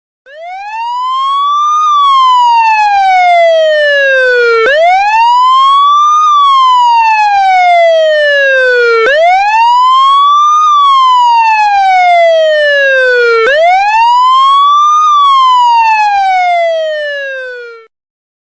사이렌.mp3